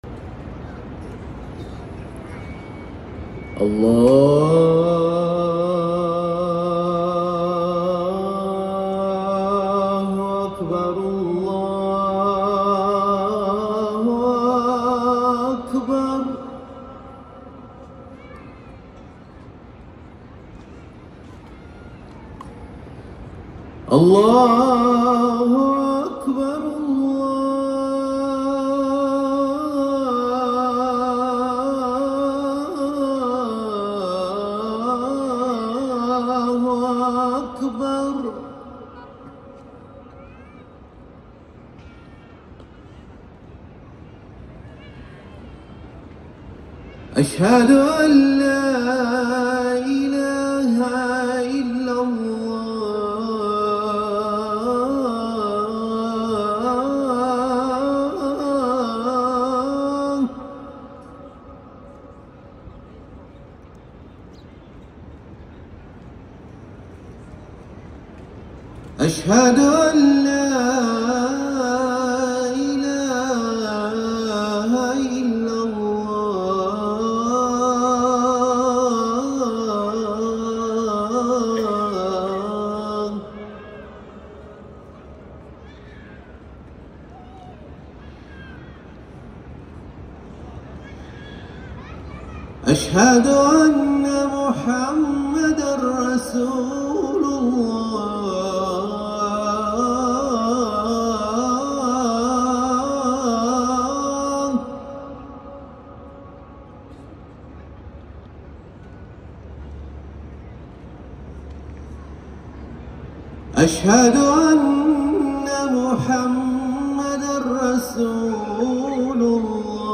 أذان الفجر الأول
الأذان الأول لصلاة الفجر